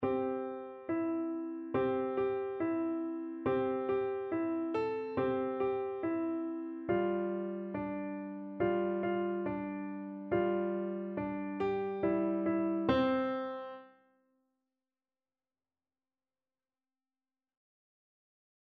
Piano version
4/4 (View more 4/4 Music)
Very Fast = c.140
Piano  (View more Beginners Piano Music)